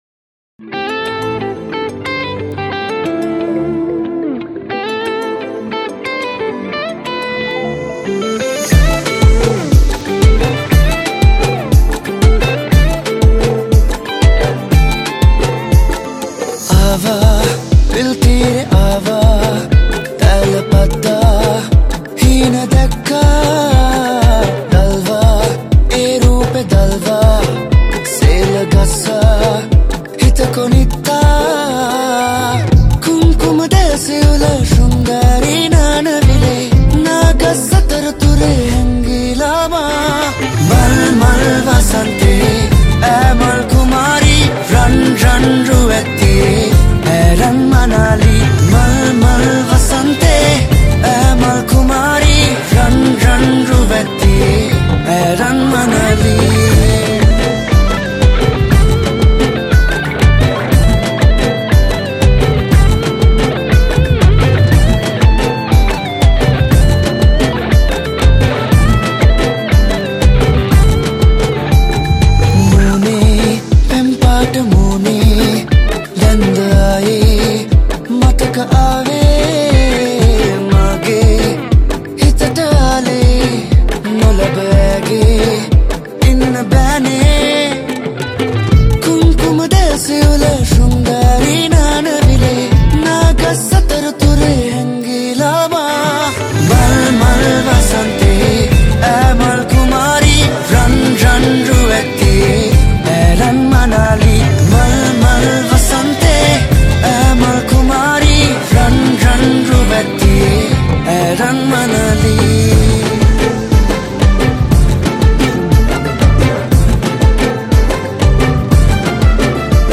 Guitars
Sri Lankan Drums